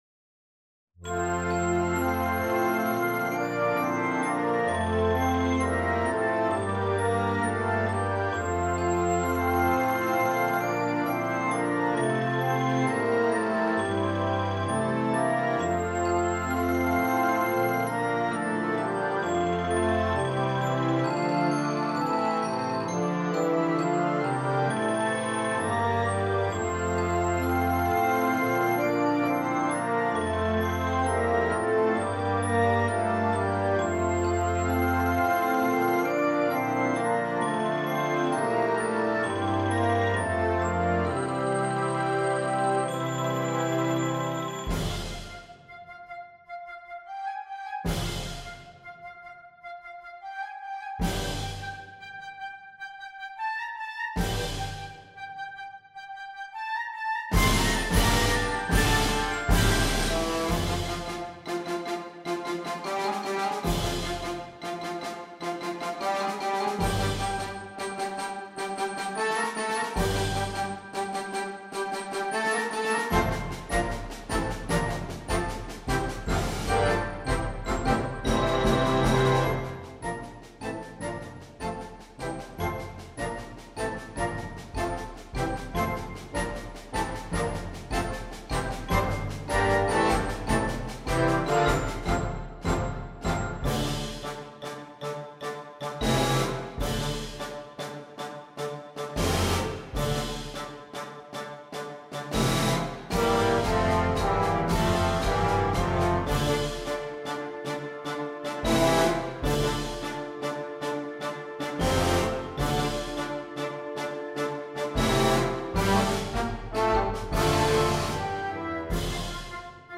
is filled with high energy and constant movement.